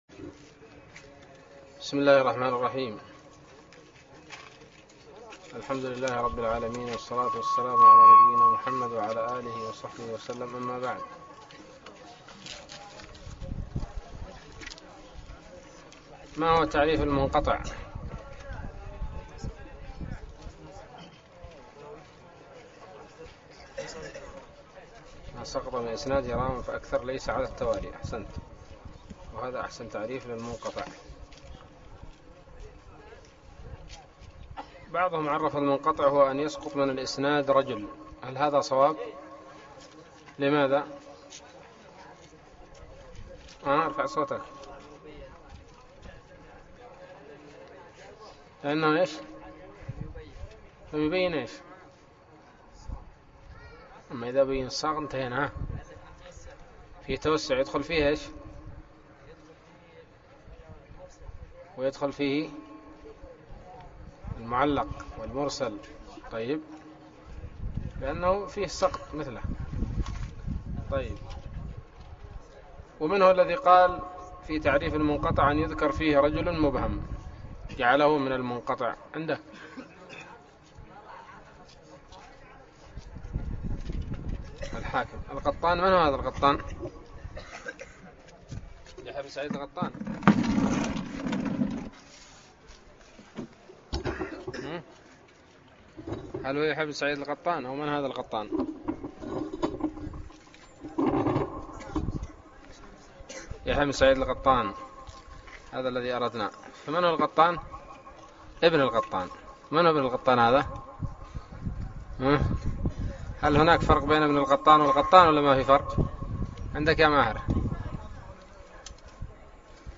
الدرس الثامن عشر من الباعث الحثيث